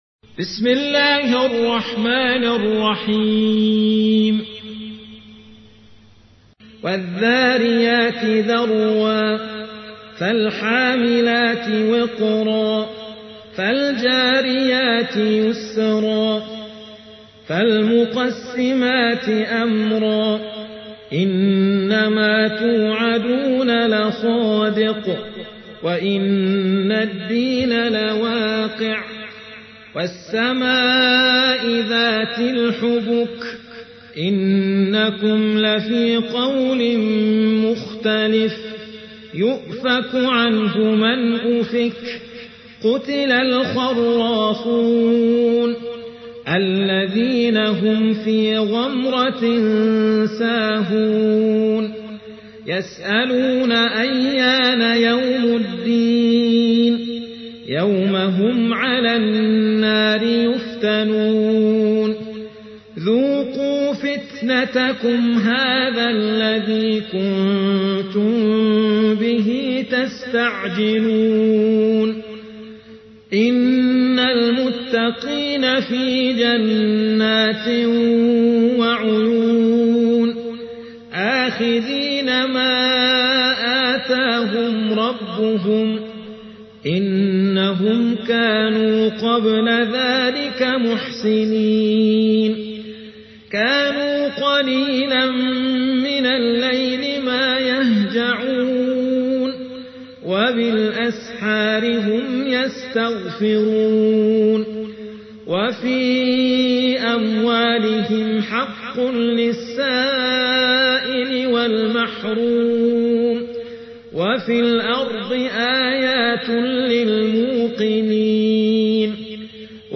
51. سورة الذاريات / القارئ